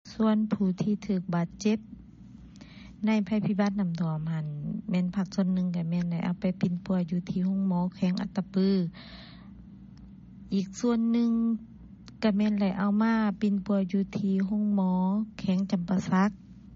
ເຊີນຟັງສຽງ ຂອງປະຊາຊົນ ທ່ານນຶ່ງ ຢູ່ເມືອງປາກເຊ (2)